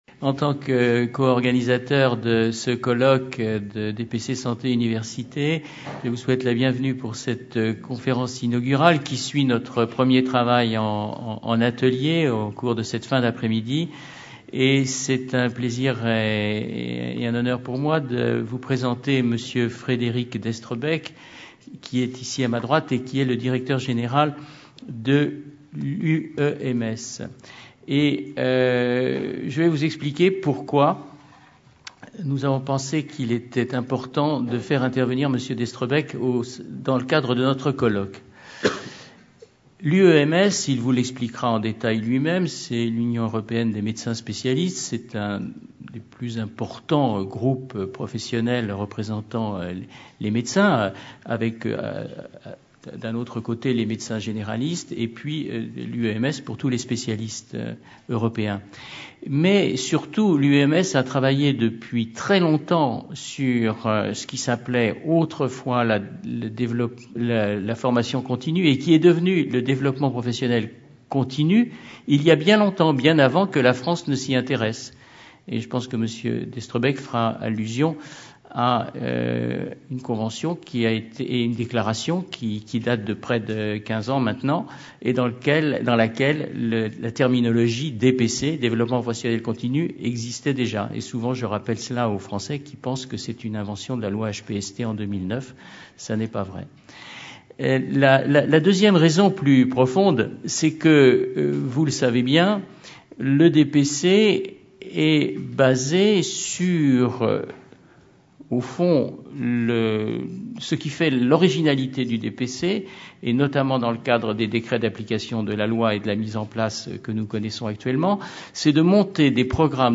Conférence enregistrée lors du colloque DPC-SU le jeudi 5 juin 2014 à Paris.